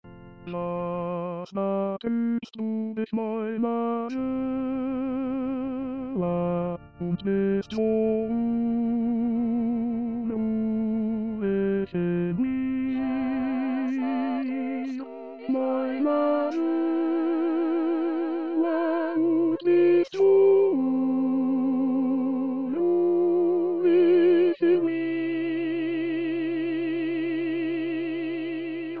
Introduction 5 voix, mes.  1-13